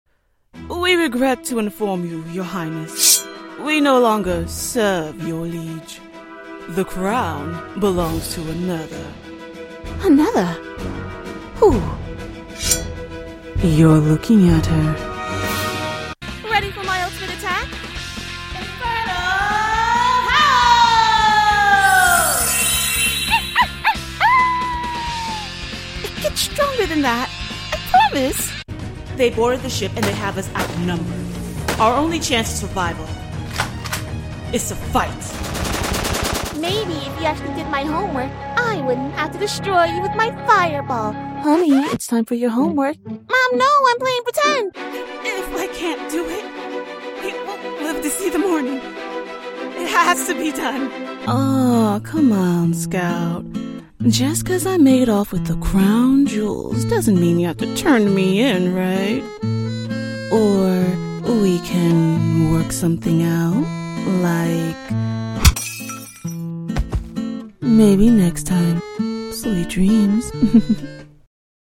Teenager, Adult, Young Adult Has Own Studio I've been voice acting for several years now
Location: Richmond, TX, USA Languages: english 123 Accents: black us standard us Voice Filters: VOICEOVER GENRE: animation commercial e-learning gaming